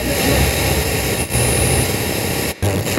80BPM RAD1-L.wav